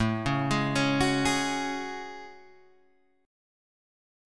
A Major 13th